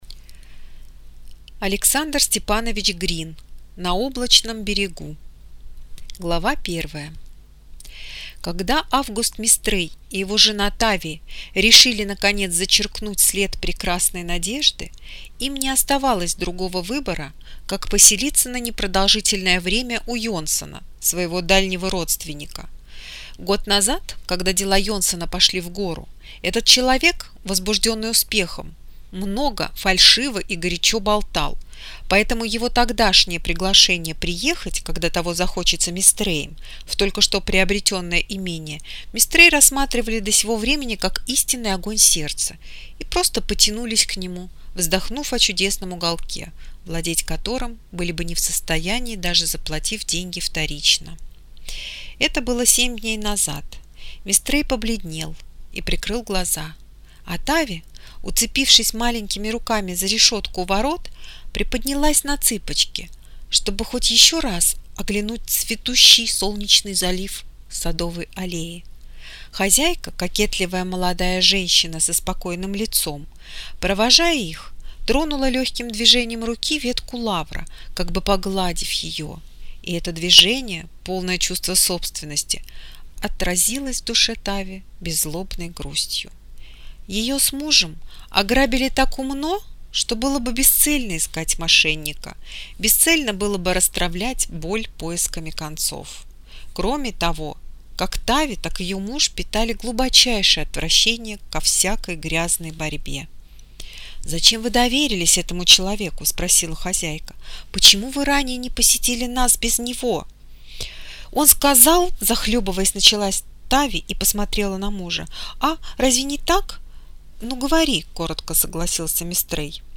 Аудиокнига На облачном берегу | Библиотека аудиокниг